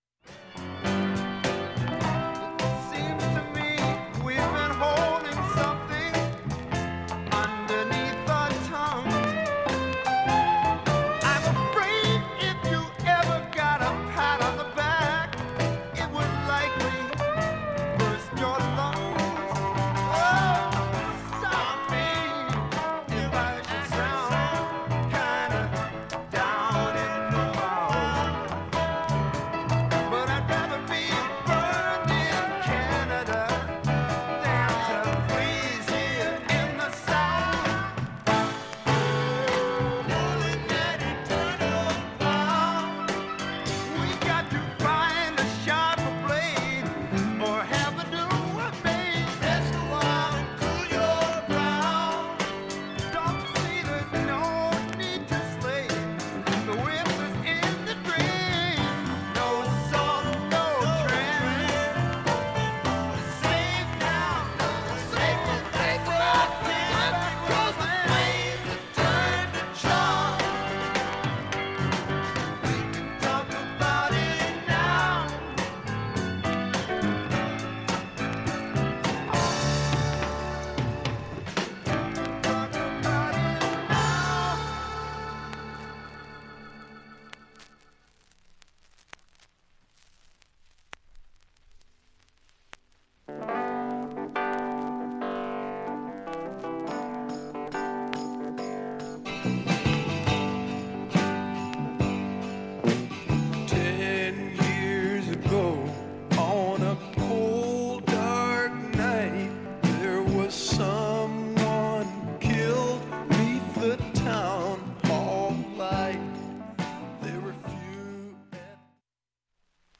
普通に聴けます音質良好全曲試聴済み。
４分の間に周回プツ出ますがかすかです。